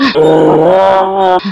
Index of /server/sound/npc/poisonzombie
zombie_voice_idle1.wav